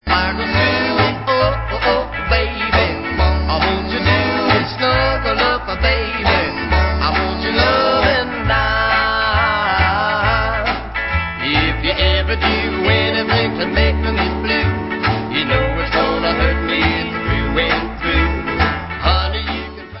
50's rock